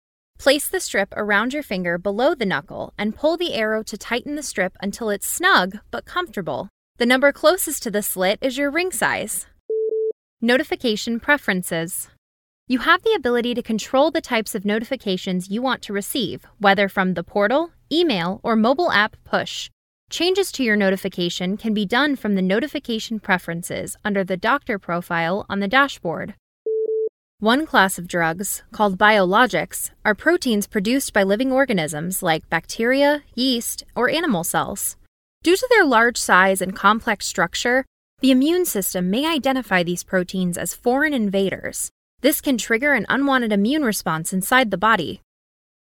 Female Voice Over, Dan Wachs Talent Agency.
Upbeat, Modern, Warm, Conversational.
eLearning